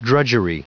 Prononciation du mot drudgery en anglais (fichier audio)
Prononciation du mot : drudgery